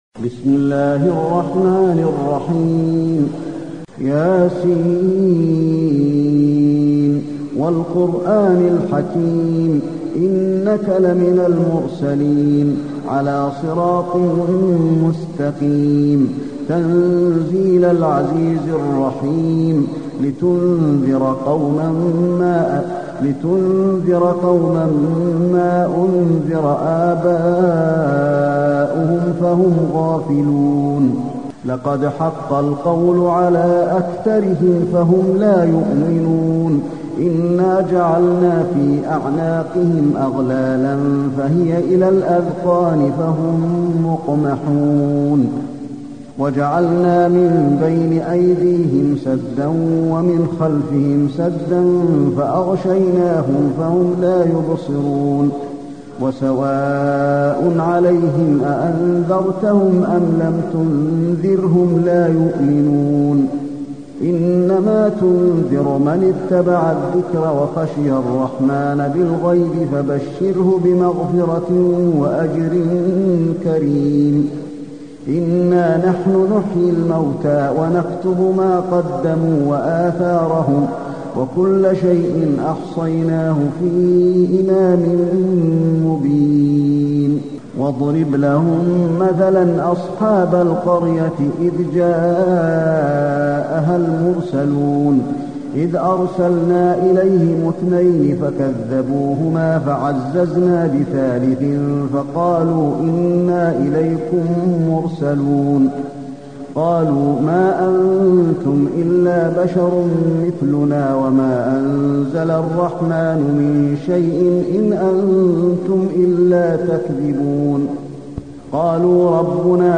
المكان: المسجد النبوي يس